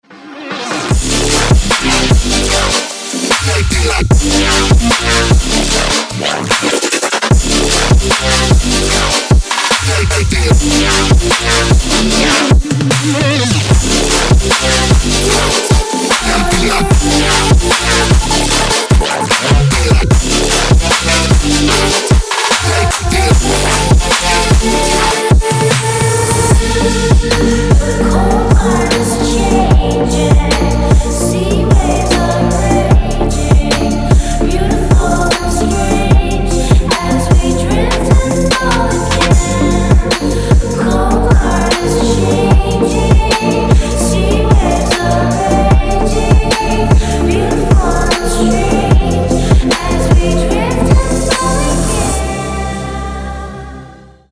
Отменный даб, красивый вокал - отличный рингтон!